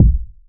• Urban Kick B Key 425.wav
Royality free kick drum single shot tuned to the B note. Loudest frequency: 104Hz
urban-kick-b-key-425-ExW.wav